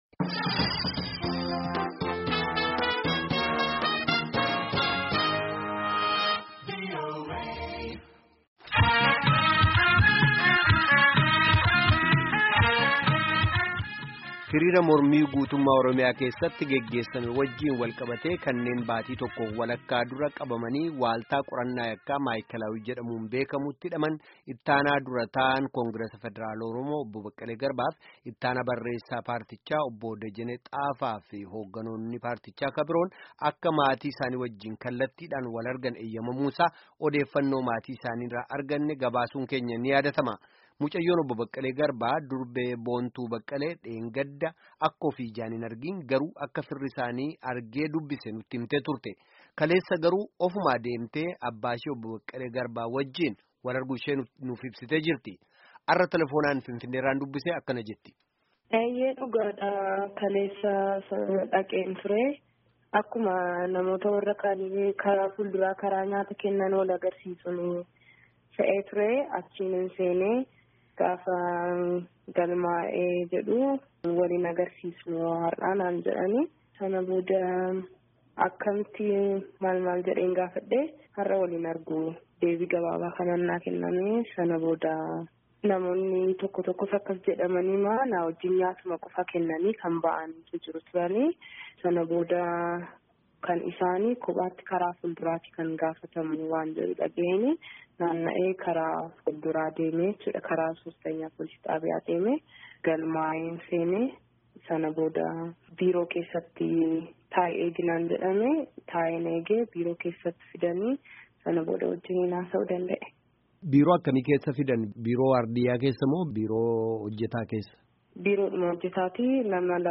Gaaffii fi deebii guutuu